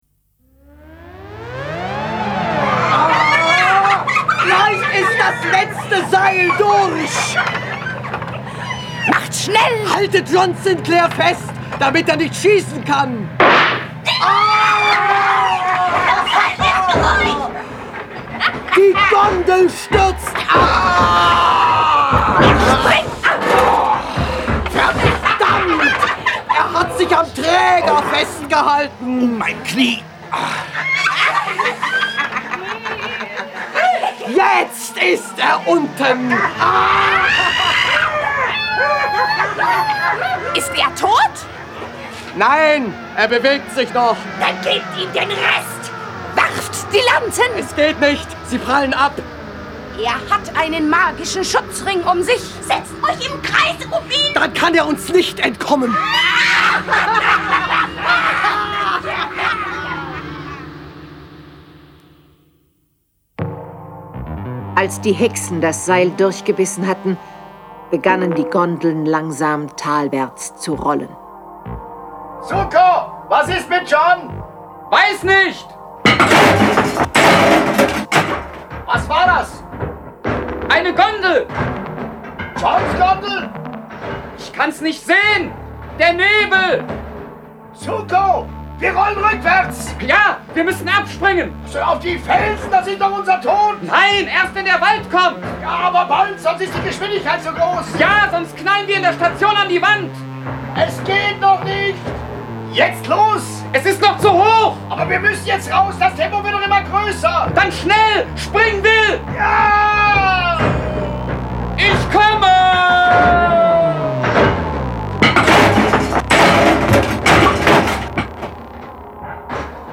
Jason Dark (Autor) diverse (Sprecher) Audio-CD 2016 | 1.